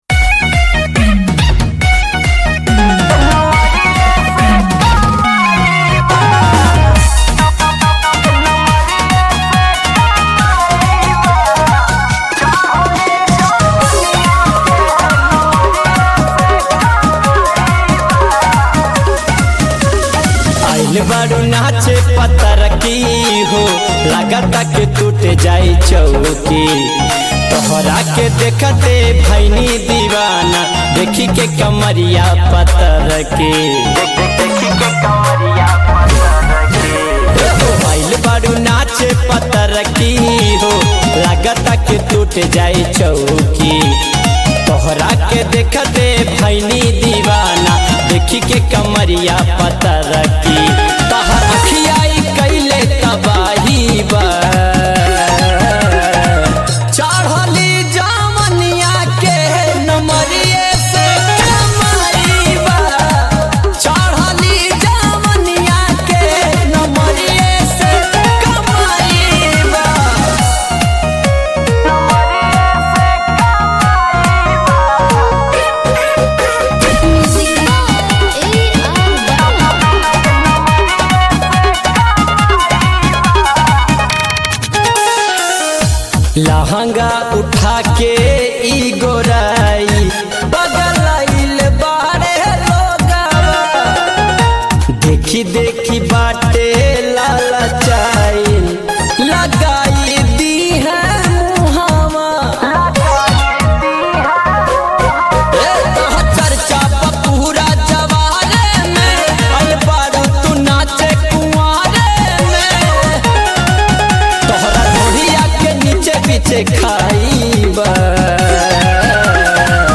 भोजपुरी आर्केस्ट्रा